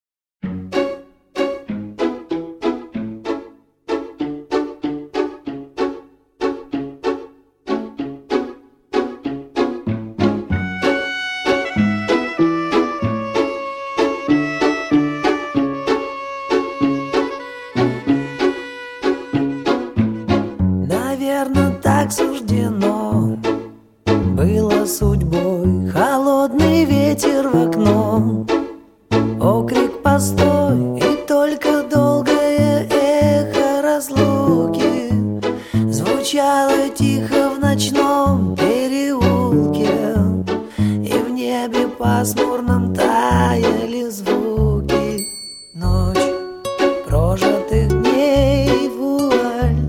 Рок
Новый цифровой ремастеринг.